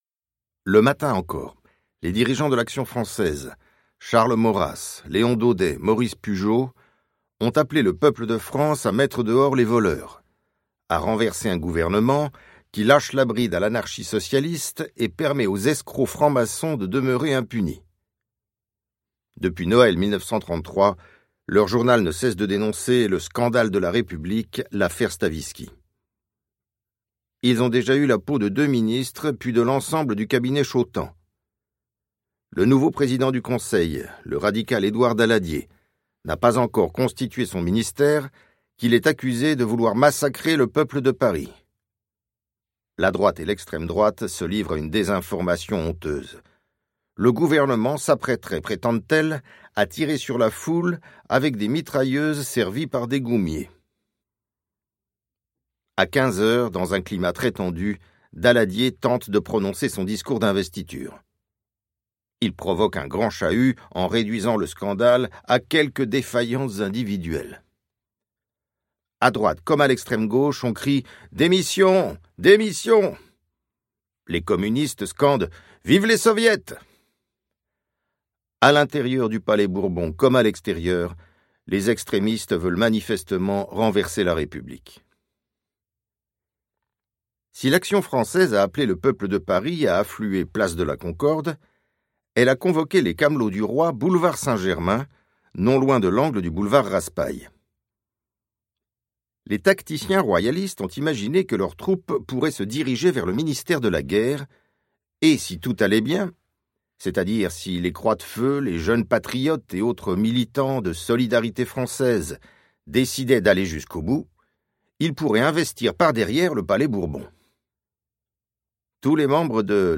Livre audio Vies et morts de Jean Moulin de Pierre Péan | Sixtrid
Texte : Intégral